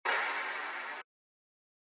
Metro Claps [Verb].wav